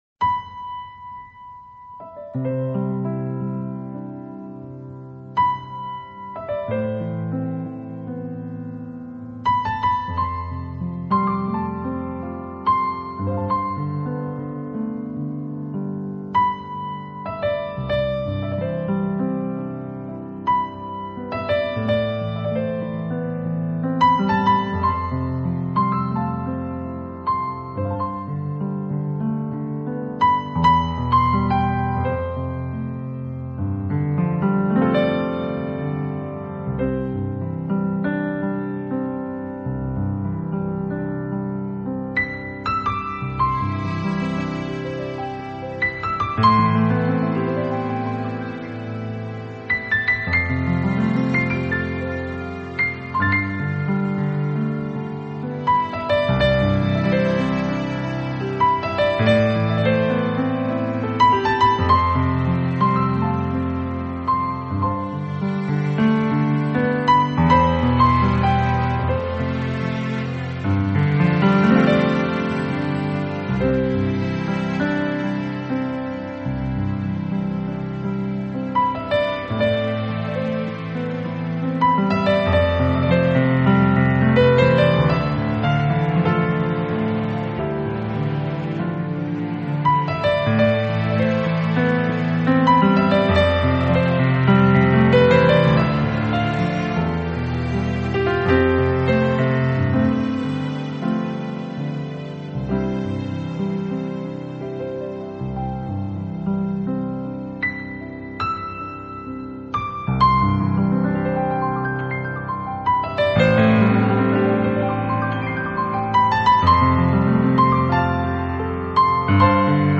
[20/9/2008]钢琴曲--(秋忆)